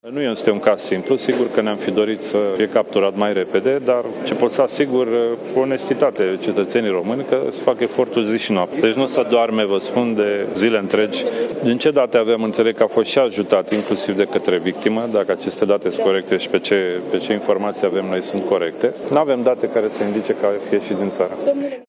Cătălin Predoiu, ministrul de Interne: A fost ajutat inclusiv de către victimă